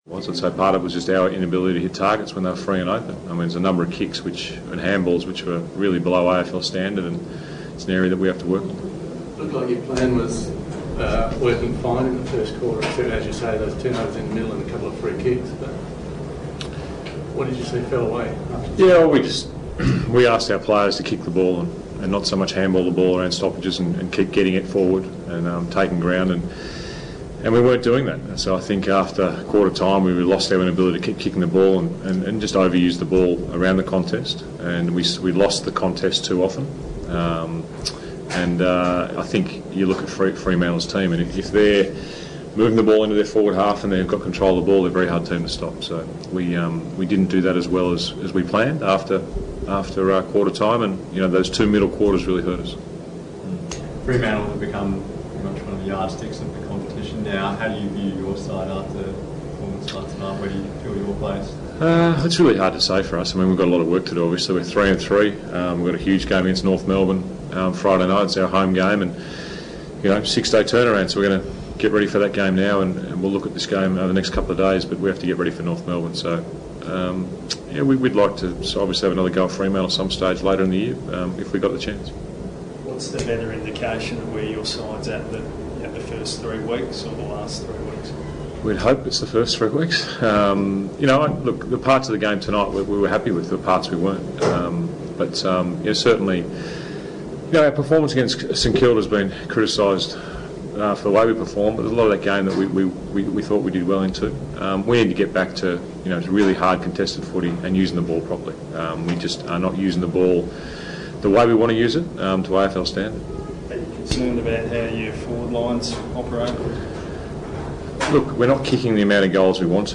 James Hird's post-match press conference after the round six loss to the Dockers.